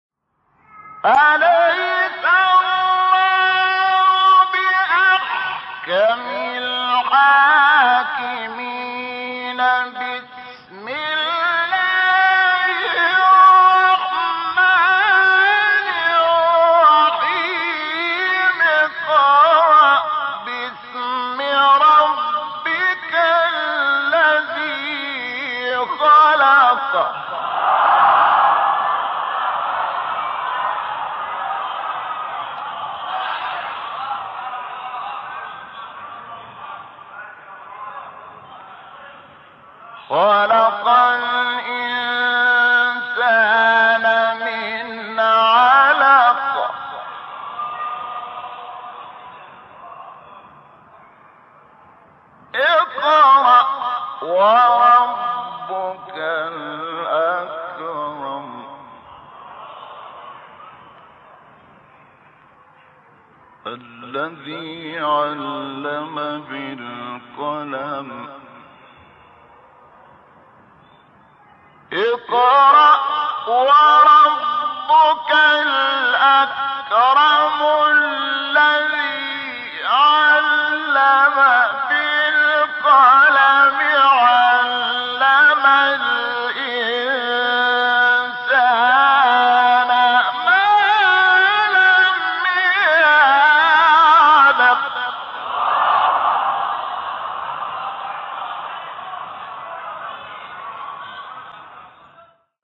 تلاوت سوره تین و علق از استاد شحات | نغمات قرآن | دانلود تلاوت قرآن